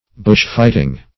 Bushfighting \Bush"fight`ing\, n.